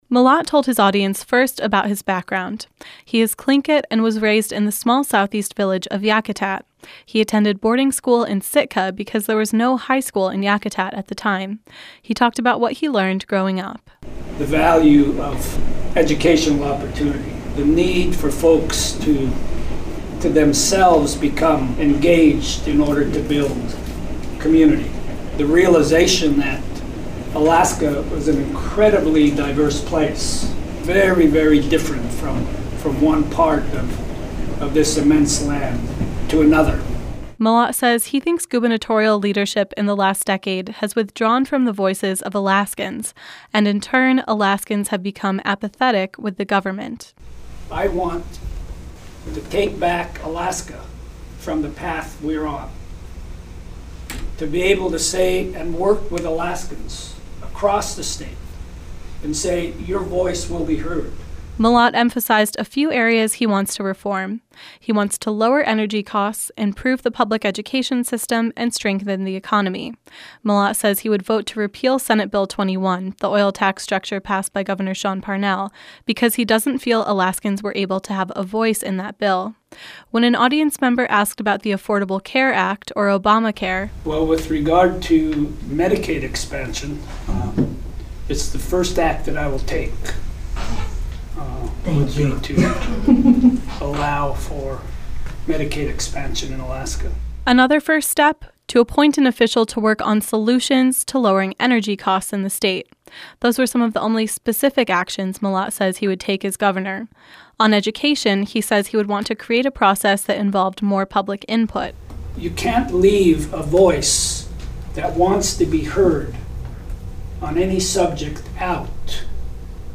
Byron Mallott spoke at a breakfast held by District 36 Democrats at the Ketchikan Yacht Club.
In Ketchikan, he spoke to about 15 people at a pancake breakfast organized by District 36 Democrats.